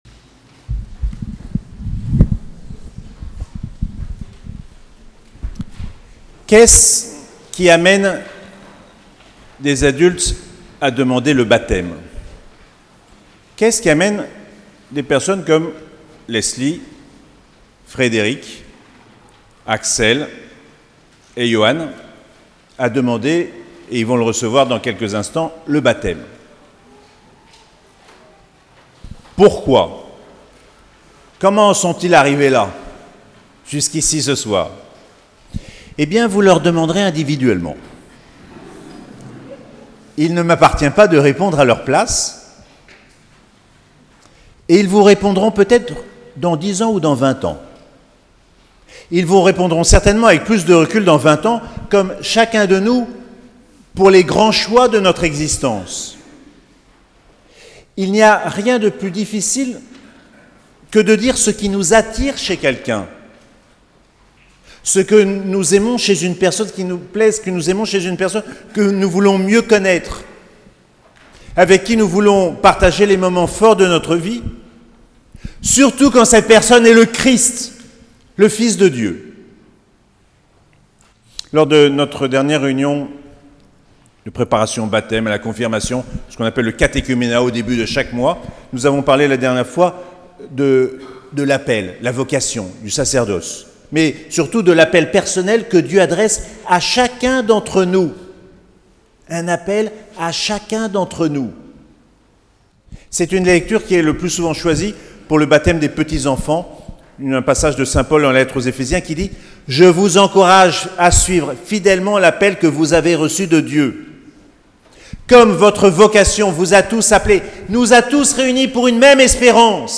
Nuit de Pâques - 20 au 21 avril 2019